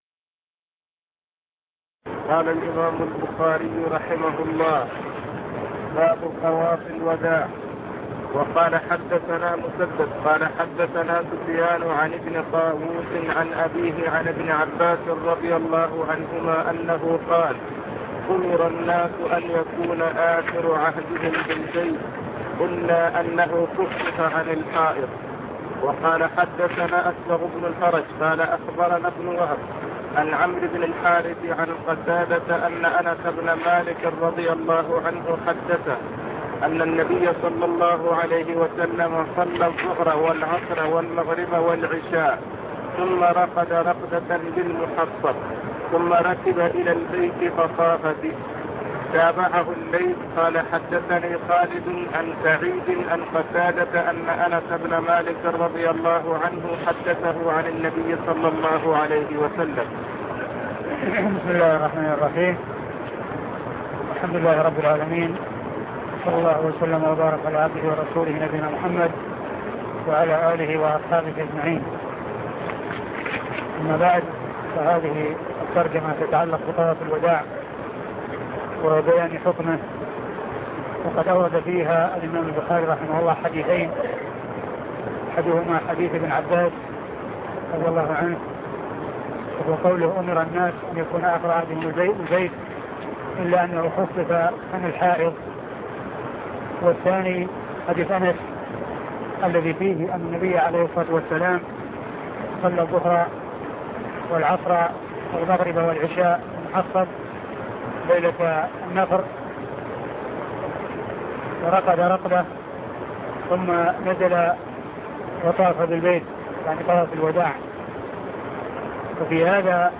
صحيح البخاري شرح الشيخ عبد المحسن بن حمد العباد الدرس 210